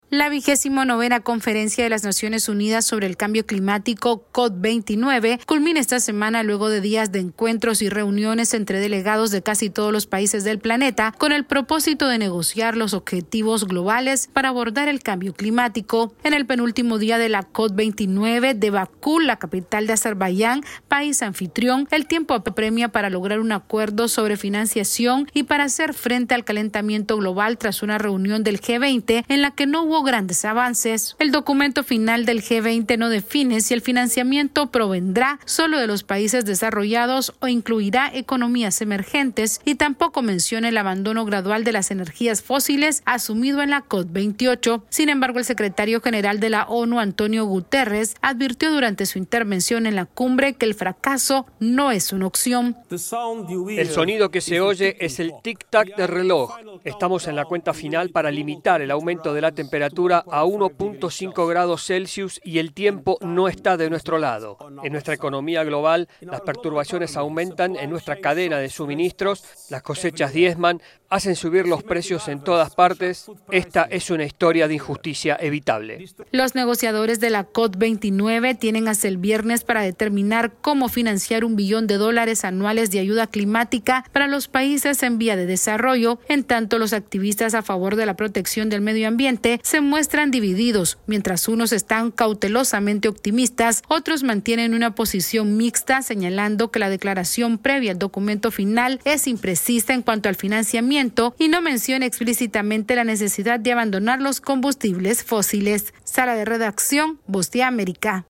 La COP29 de Bakú se aproxima a su fin con la urgencia de lograr un acuerdo sobre el financiamiento contra el calentamiento global ante la mirada atenta de ambientalistas que exigen una pronta solución a este problema. Esta es una actualización de nuestra Sala de Redacción.